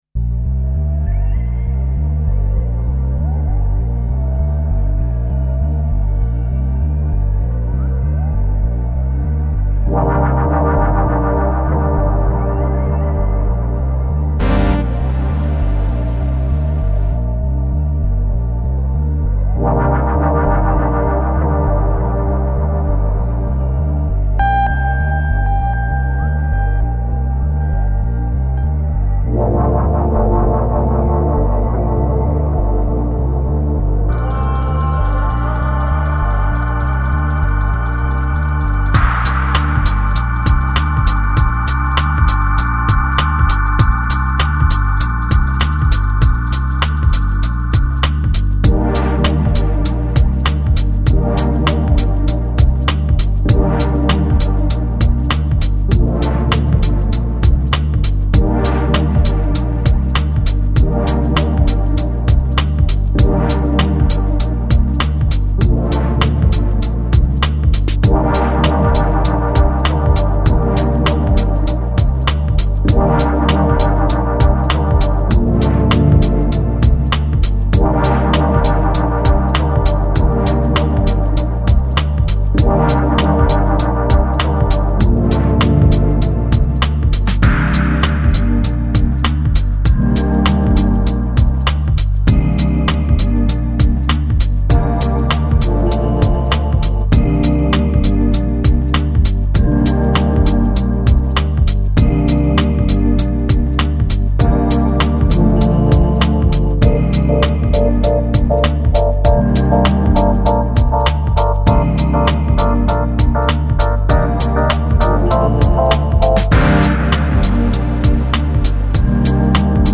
Electronic, Downtempo, House, Chillout Год издания